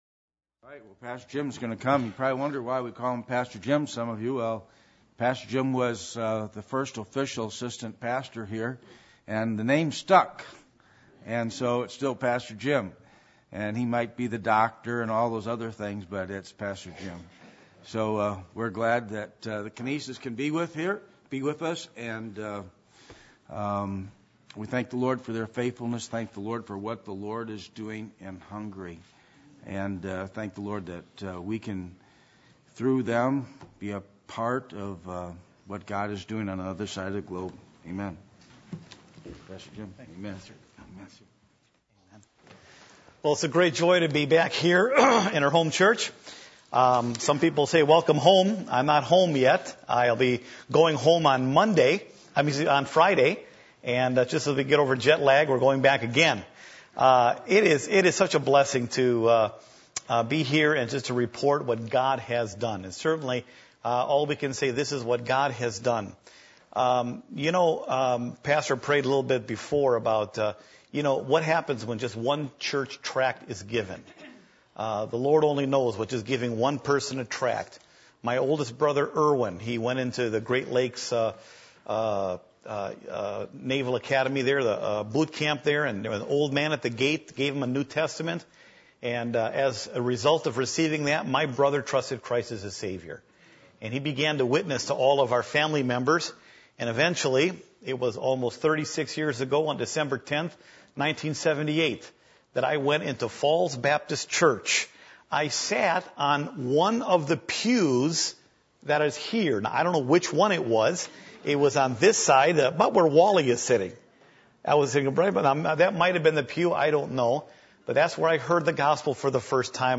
Passage: Jeremiah 1:1-9 Service Type: Sunday Evening %todo_render% « What Does It Take For Real Spiritual Change To Take Place In Our Lives?